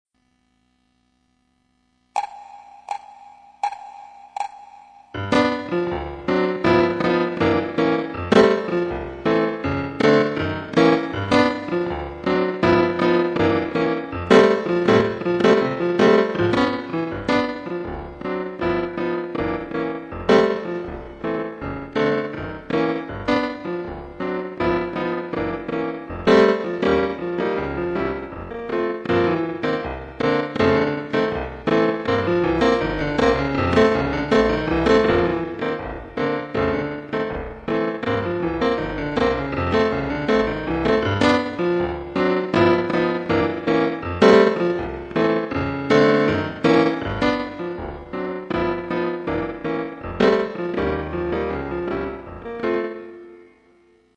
Merengue Piano Slower